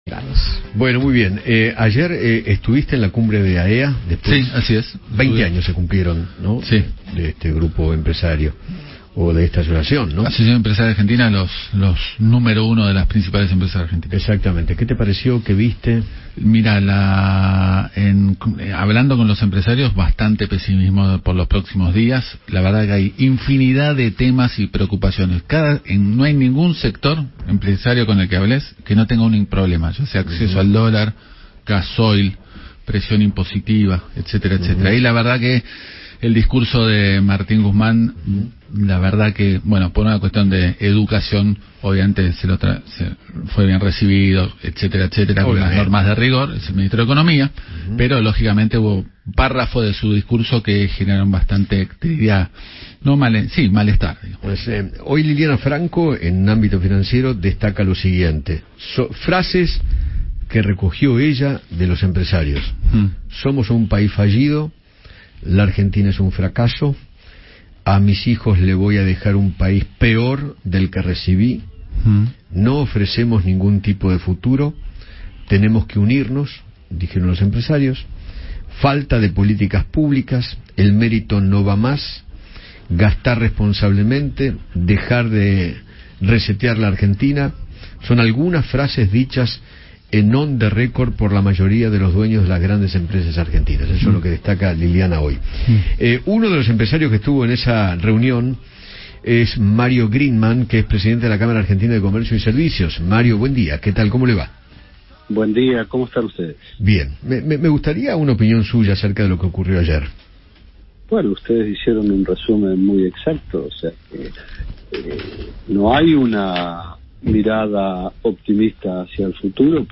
conversó con Eduardo Feinmann sobre la Cumbre de AEA.